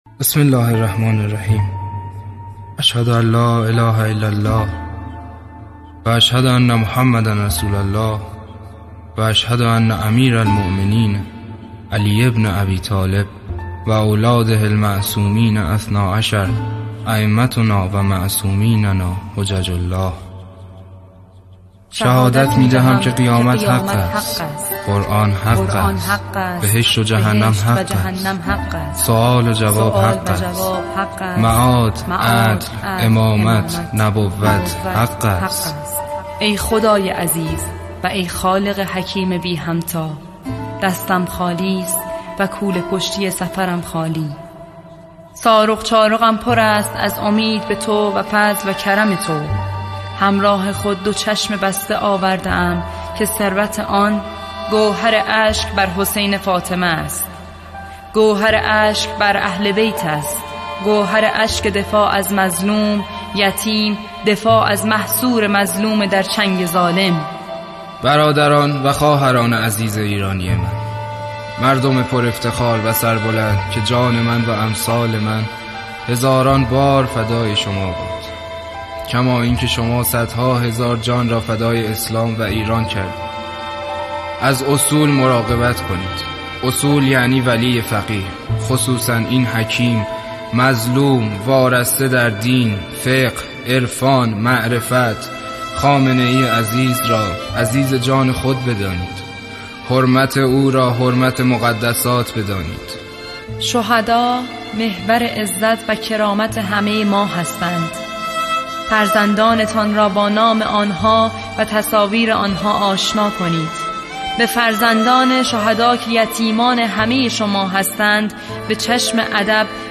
آنچه خواهید دید فایلی با کیفیت از وصیتنامه تصویری حاج قاسم سلیمانی است که با صدای فرزندان ایشان در استودیو به اجرا درآمده است.
فایل صوتی وصیتنامه